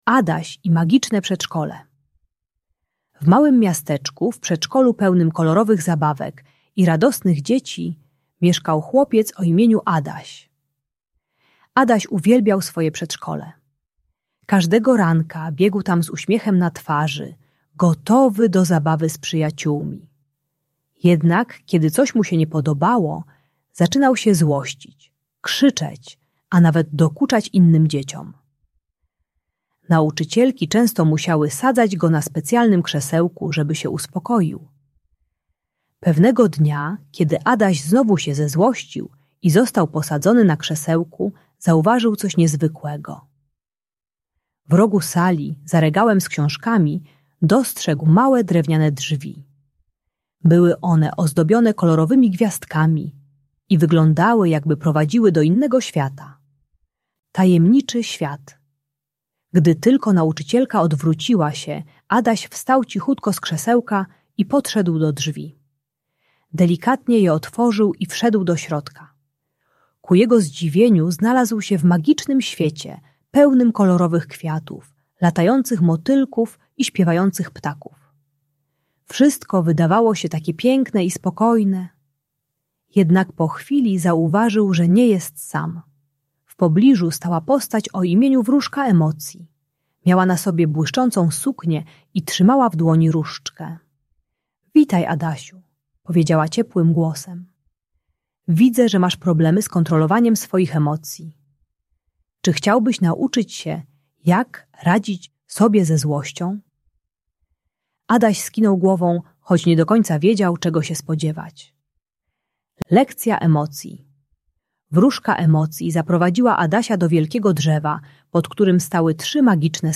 Adaś i Magiczne Przedszkole - Bunt i wybuchy złości | Audiobajka
Przeznaczona dla dzieci 3-5 lat, uczy trzech technik radzenia sobie ze złością: głębokiego oddychania na poduszce, rysowania emocji w notesiku oraz relaksacji z kulą spokoju. Darmowa audiobajka o kontrolowaniu emocji dla przedszkolaka.